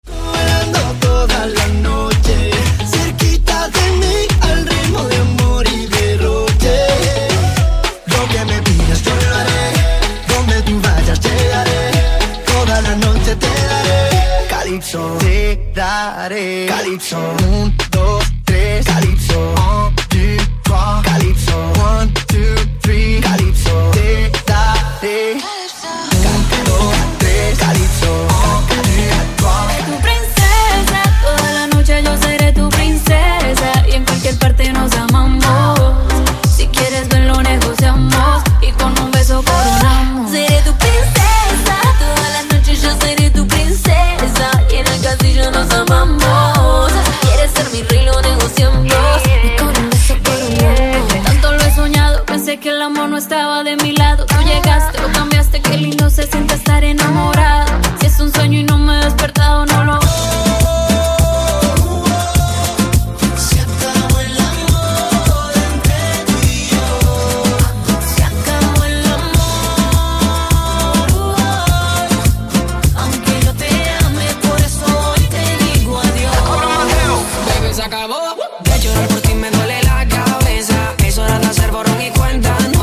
Popular Latin - High Tempo
Best of High Tempo Latin Music